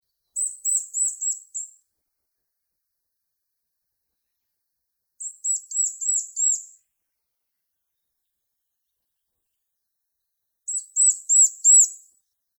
「日本の鳥百科」ゴジュウカラの紹介です（鳴き声あり）。
gojuukara_ch.mp3